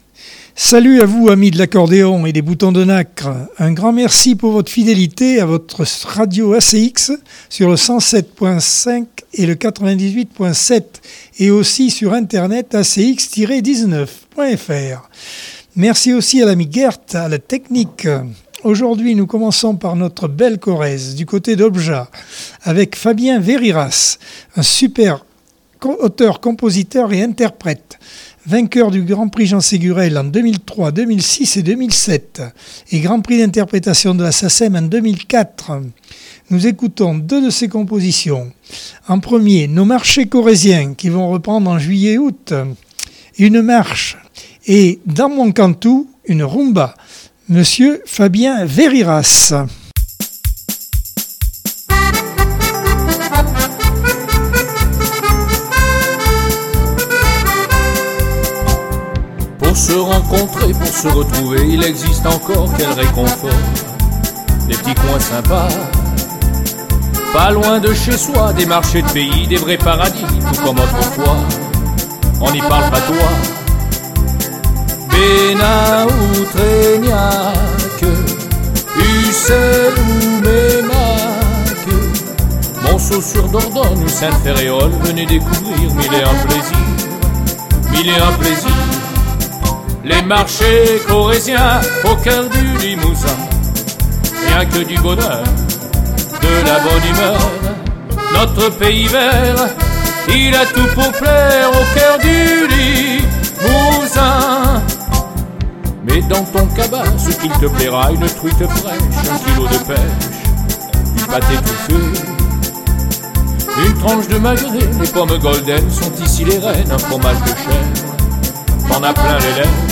Accordeon 2022 sem 20 bloc 1.